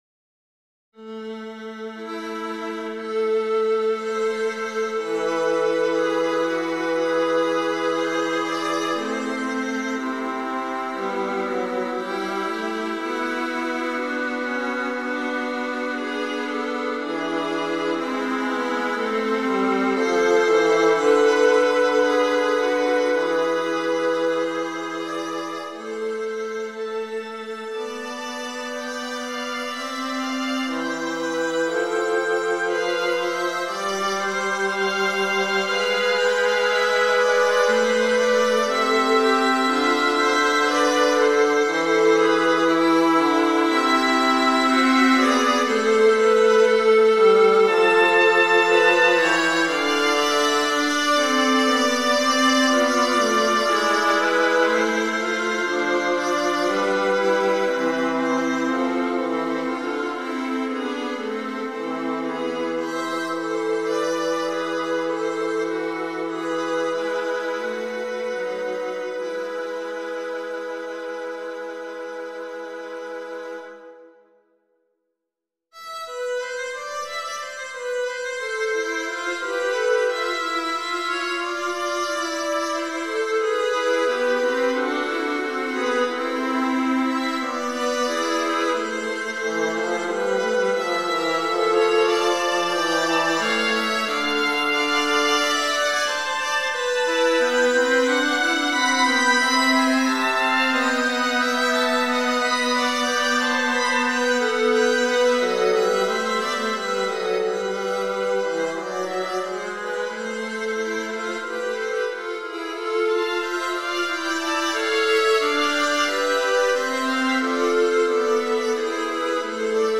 From one of the Baroque greats